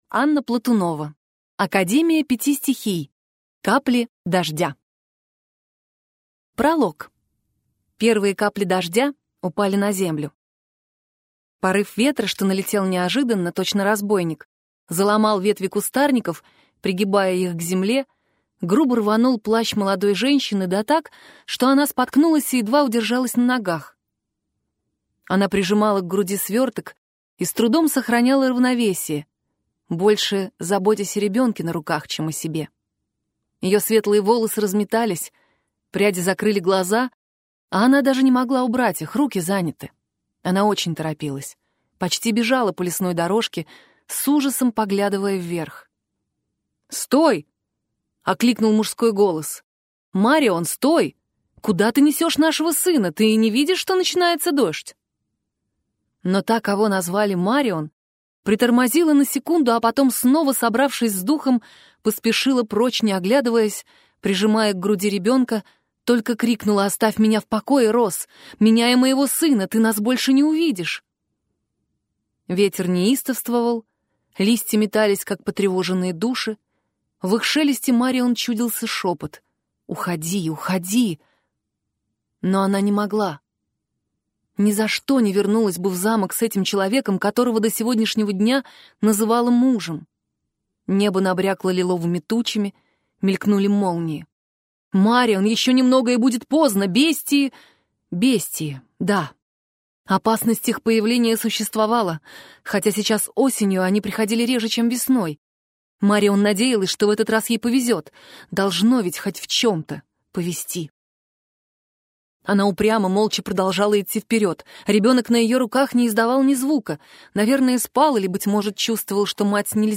Аудиокнига Академия Пяти Стихий. Капли дождя | Библиотека аудиокниг